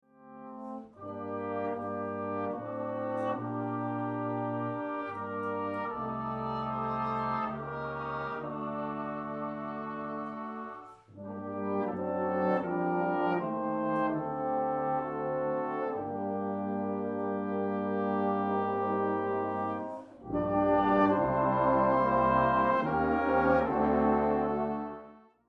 (Full Brass)
arranged in this version for full Brass Band